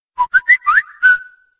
Звуки на звонок
Нарезка на смс или будильник